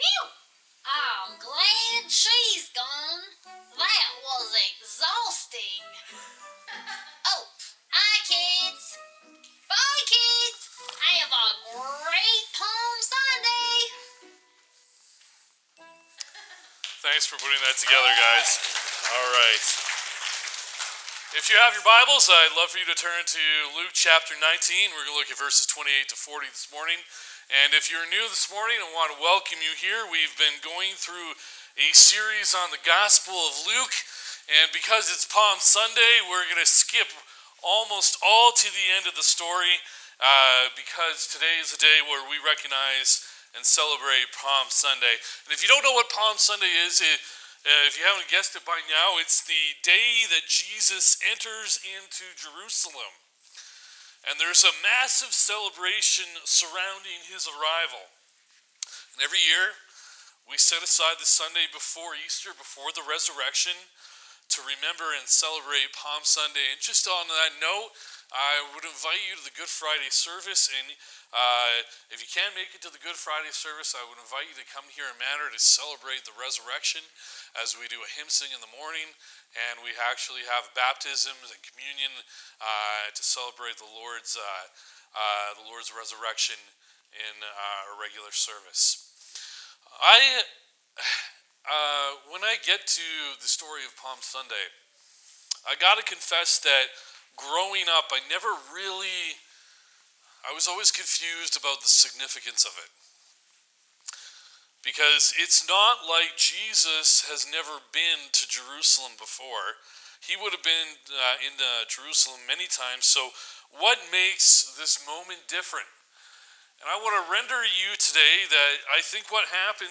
Download Download From this series Current Sermon Make Noise This Is Jesus: The Gospel of Luke - Part 1